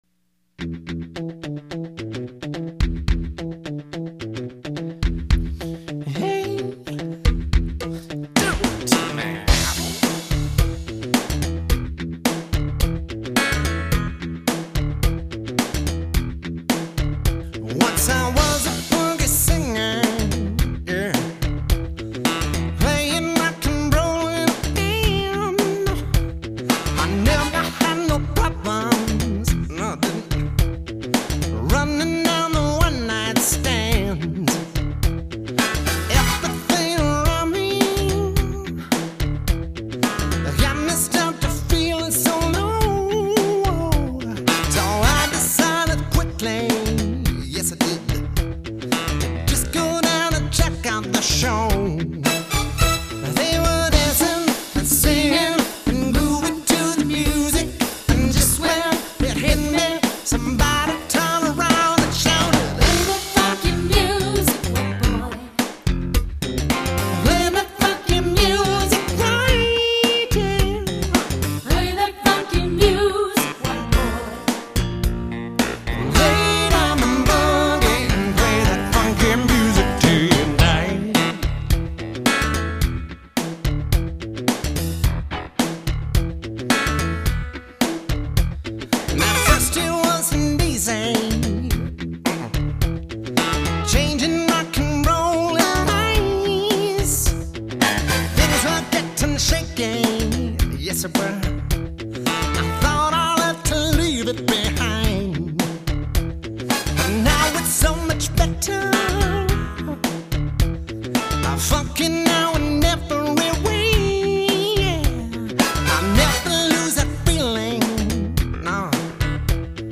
(Good Ol' Dance Song)
for her back-up vocal help!